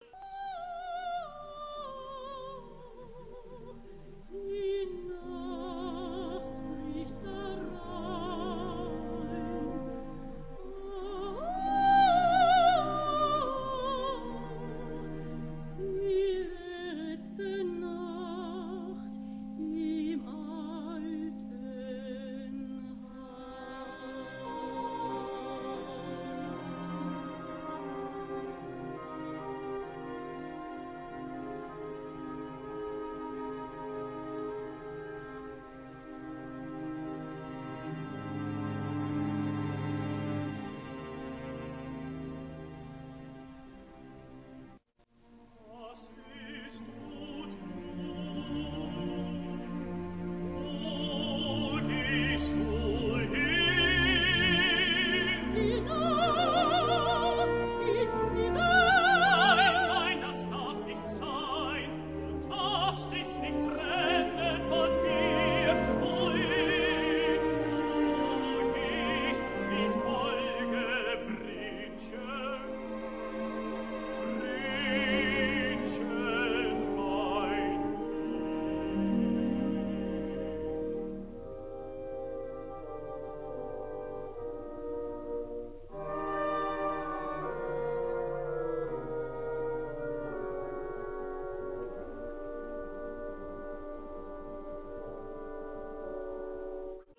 Sopran
Tenor